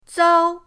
zao1.mp3